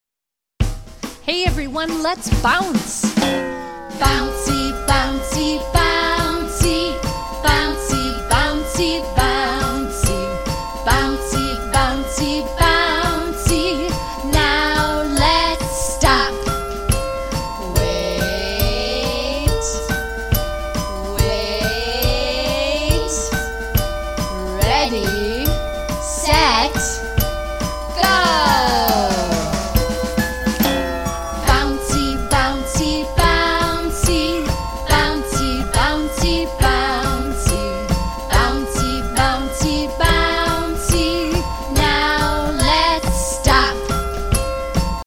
Interaction song